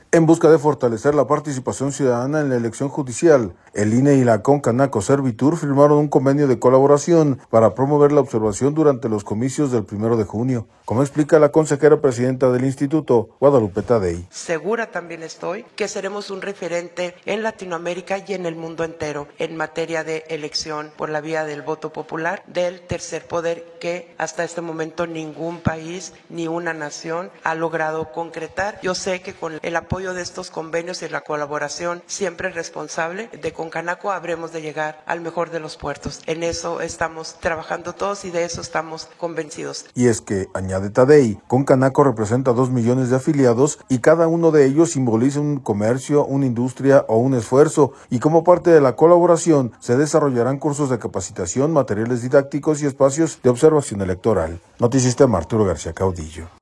En busca de fortalecer la participación ciudadana en la elección judicial el INE y Concanaco-Servytur firmaron un convenio de colaboración para promover la observación durante los comicios del 1 de junio, como explica la consejera presidenta del Instituto, Guadalupe Taddei.